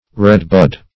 Redbud \Red"bud`\ (-b?d`), n. (Bot.)